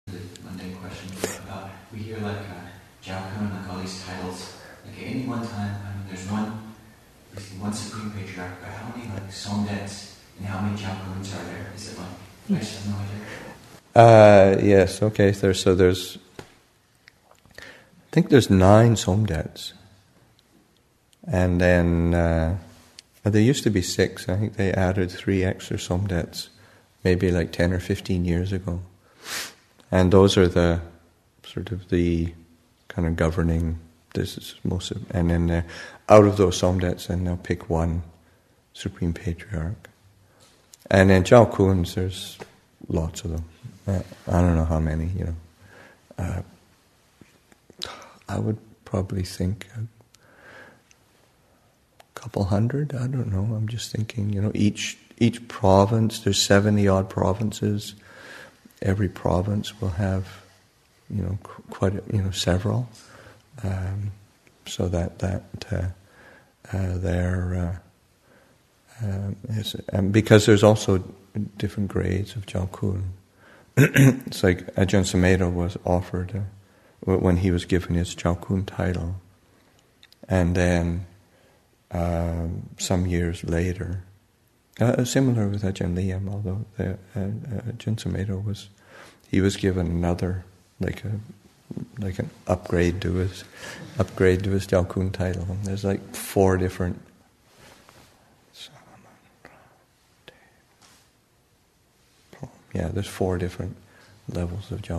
Our Roots in the Thai Forest Tradition, Session 40 – Mar. 2, 2014